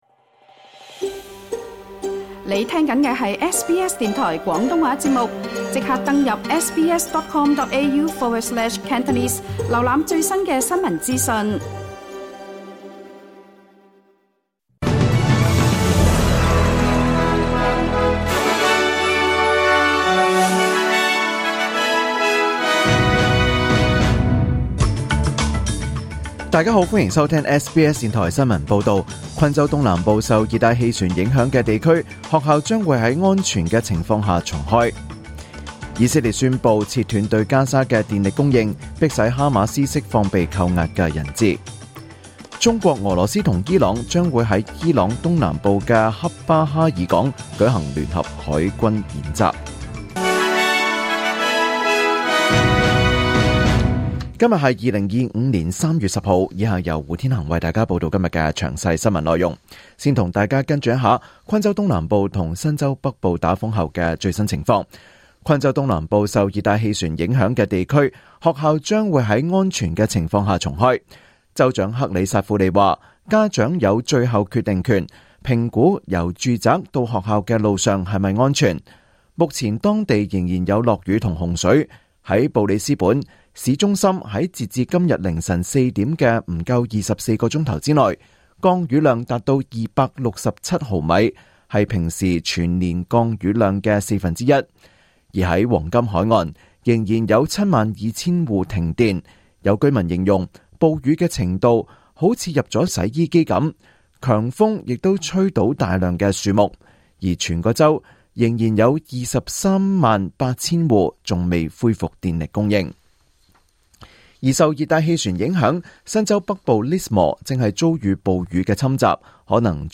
2025 年 3 月 10 日 SBS 廣東話節目詳盡早晨新聞報道。